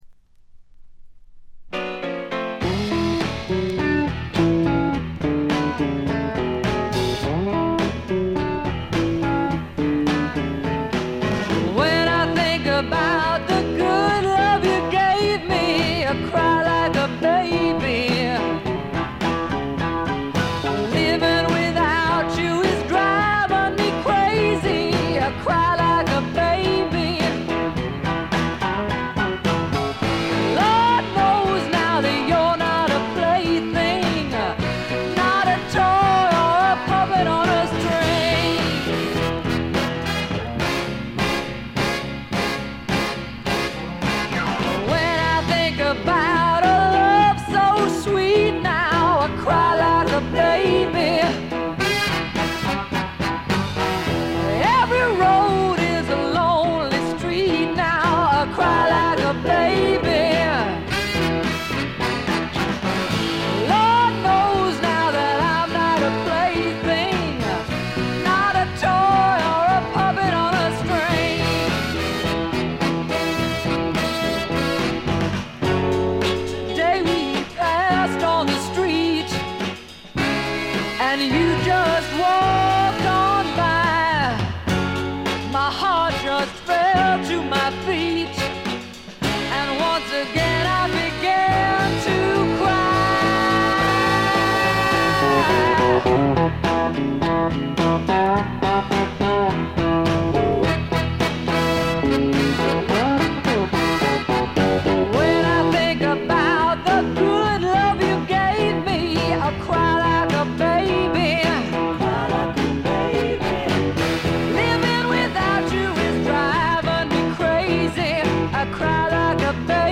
ほとんどノイズ感無し。
試聴曲は現品からの取り込み音源です。
Recorded At - Muscle Shoals Sound Studios